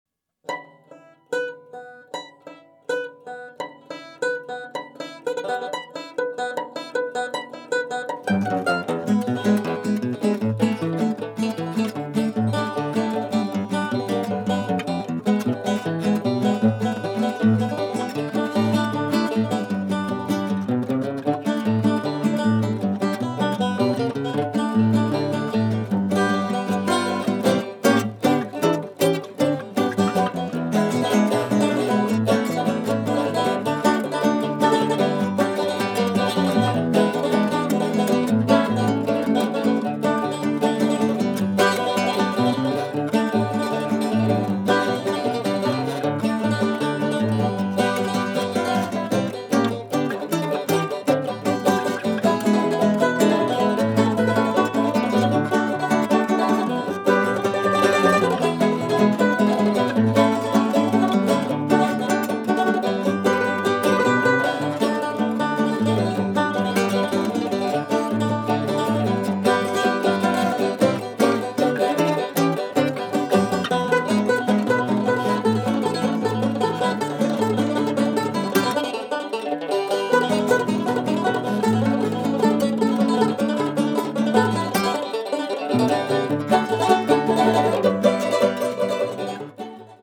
ingioiellano l'architettura musicale di un blues viscerale
banjo
evocante suggestive atmosfere di un remoto Far West